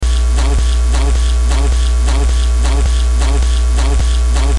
Hissing Sound in the Background - VideoHelp Forum
Here it is, exaggerated and looped. You'll hear the telltale 60hz grounding hum. (Not the part that sounds like House Music, but the ringing drone sound that you'd also hear if you ever unplugged an electric guitar from an amp)
groundloop.mp3